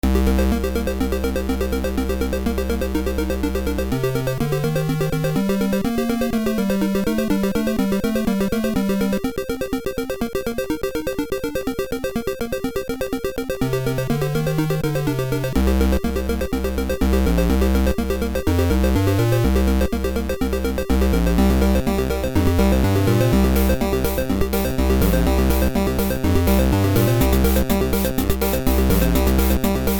Waterfall theme